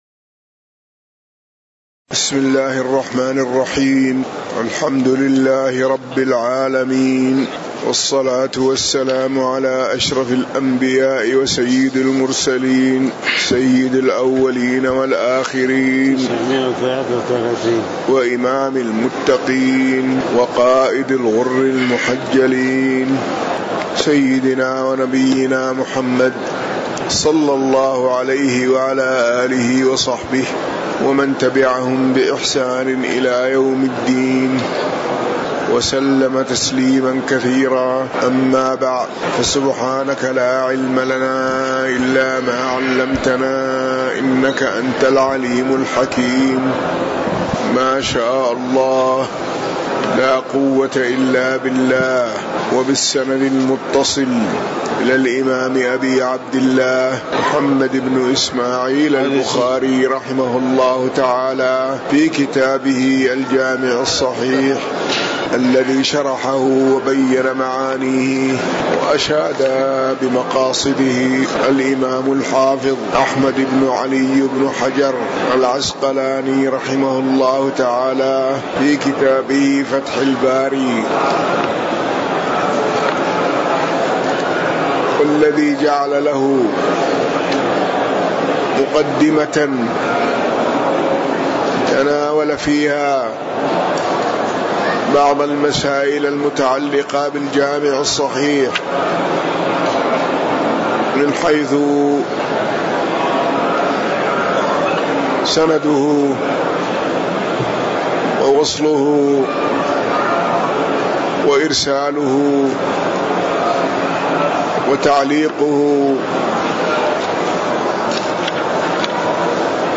تاريخ النشر ٢٠ شوال ١٤٤٠ هـ المكان: المسجد النبوي الشيخ